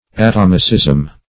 Atomicism \A*tom"i*cism\